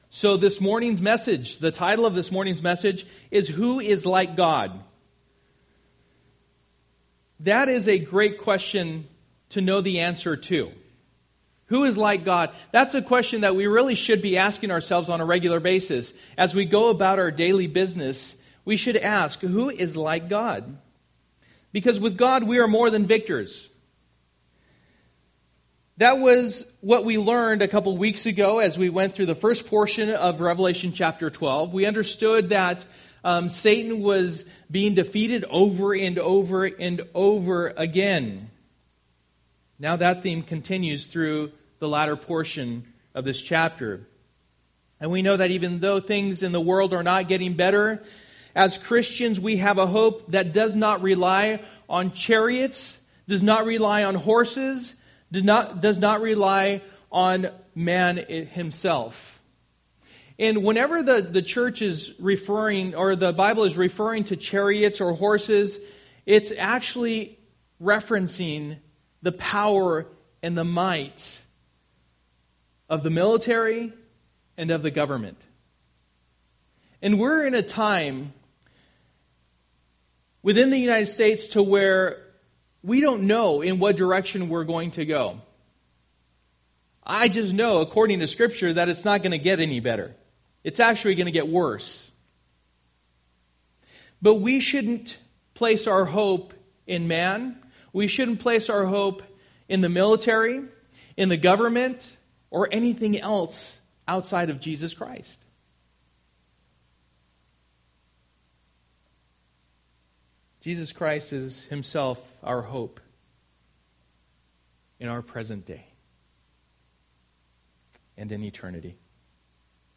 For the Time is Near Passage: Revelation 12:7-17 Service: Sunday Morning %todo_render% Download Files Bulletin « The Church Isn’t Perfect Praising